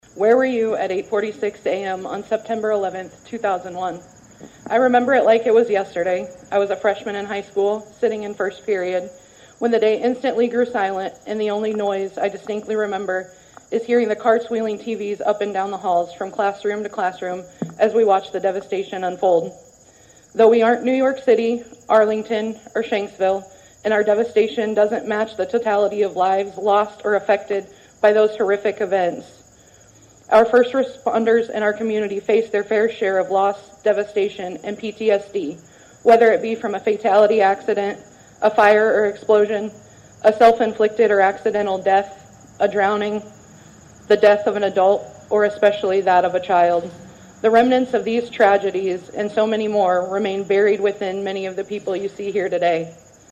(Atlantic) The American Legion Post #43 held a Remembrance Day ceremony Thursday morning at the Atlantic City Park to honor first responders for their heroic efforts during the current day and the September 11, 2001, attacks on the United States.